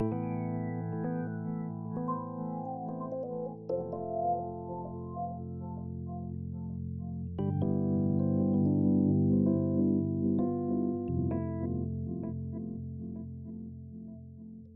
五五开 奸笑 GG
描述：嘿嘿嘿 奸笑 GG
声道立体声